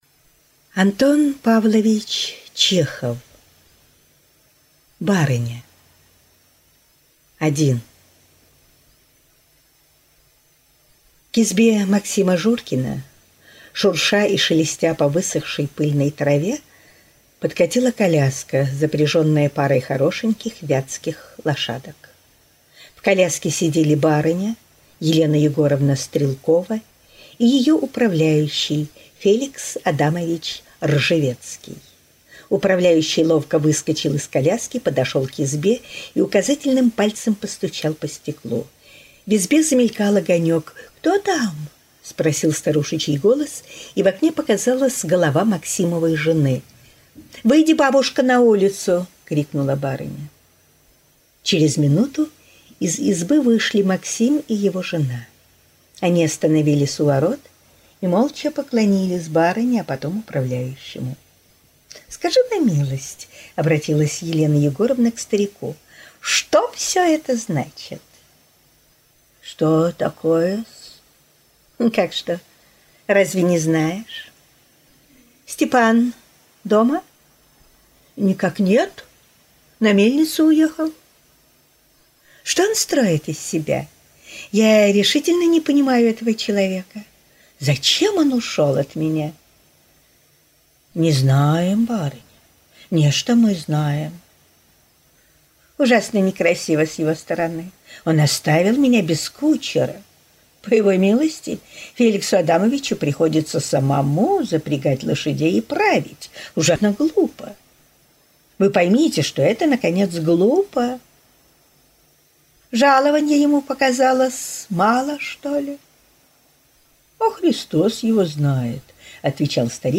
Аудиокнига Барыня из жанра Классическая проза - Скачать книгу, слушать онлайн